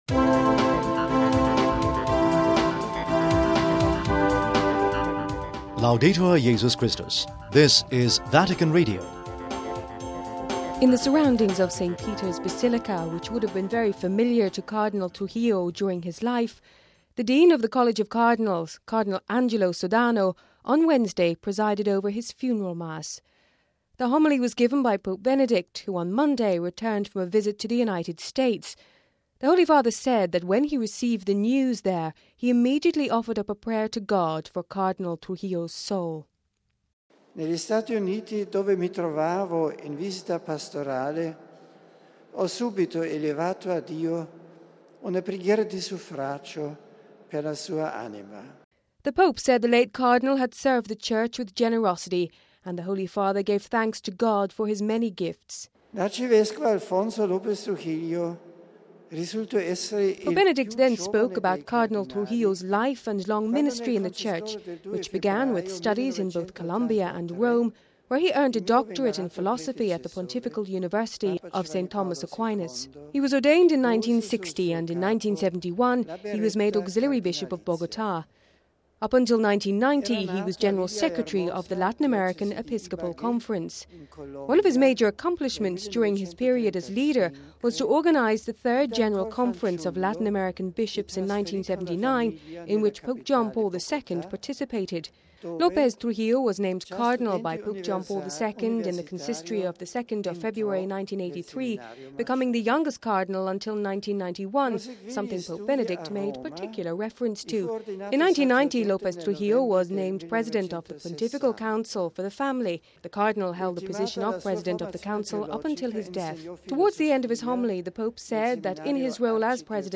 (23 Apr 08 - RV) Pope Benedict XVI gave the homily today at the Funeral Mass for Columbian Cardinal Alfonso López Trujillo, President of the Pontifical Council for the Family who died on Saturday at the age of 72. We have this report...